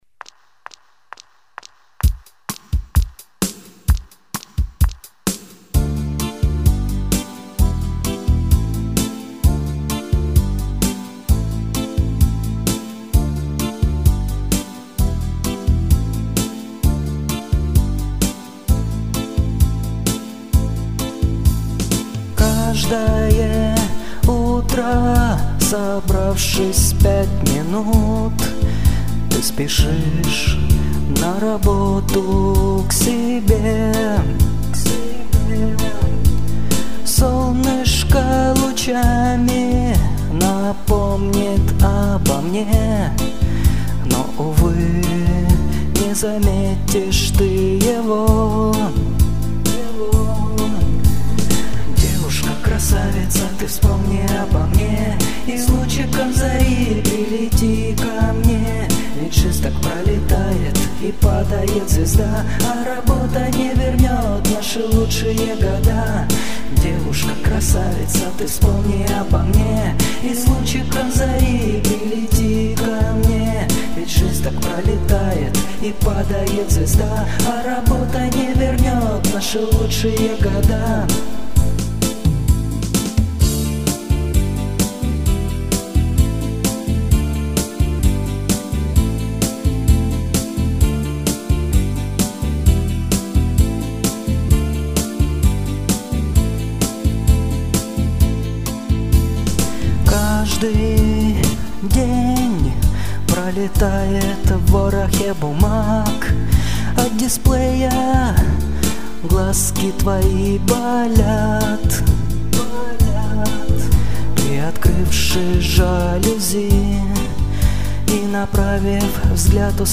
Слова, музыка, вокал - все мое родное :)
Писал все дома на комп. Играл на гитаре и синтезаторе. Пел в гарнитуру.